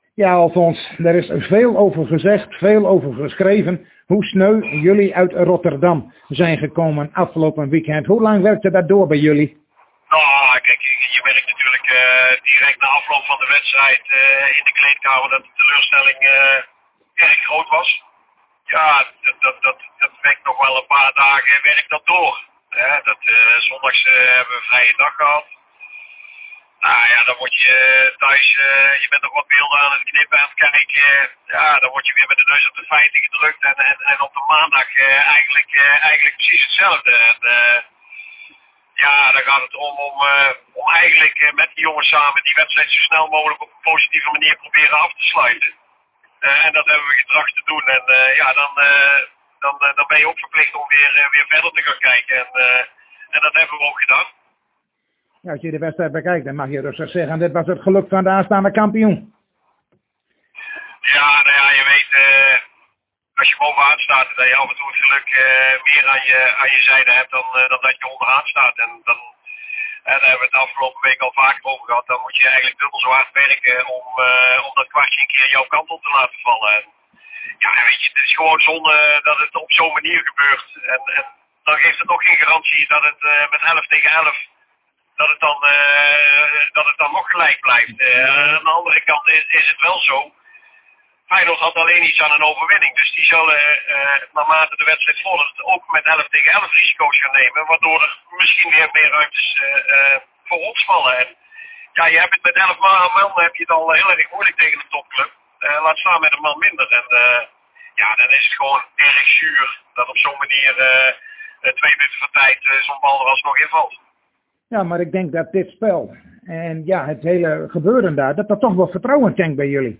In gesprek met